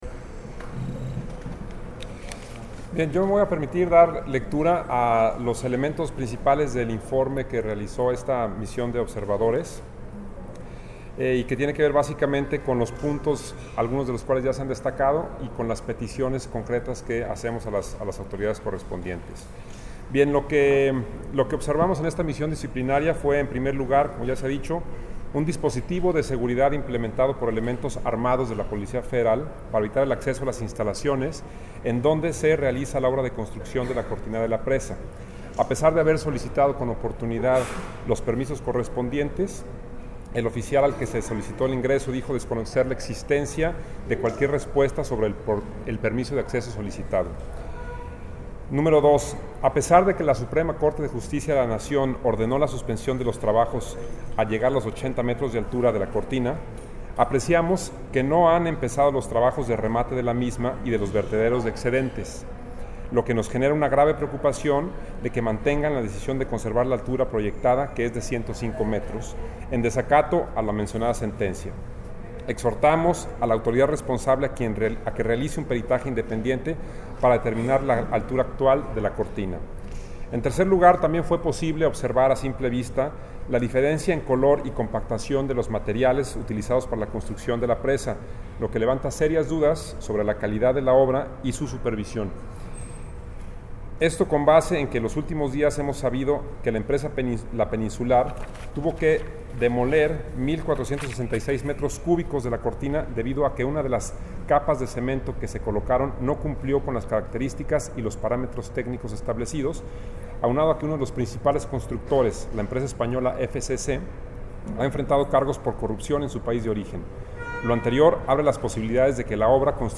En conferencia de prensa se dio a conocer el resultado de la Auditoría popular a la presa el Zapotillo que afectará a las comunidades de Temacapulin, Palmarejo y Acasico en la que se señala la violación sistemática a los Derechos Humanos, las irregularidades en la construcción de la presa el Zapotillo, el posible desacato de la sentencia emitida por la Suprema Corte de Justicia de la Nación (SCJN), el mal manejo del agua en el estado de Jalisco y se exigió al presidente Enrique Peña Nieto cumplir los derechos de los pueblos originarios dejando de lado la política extractivista que tiene consecuencias irreparables al medio ambiente.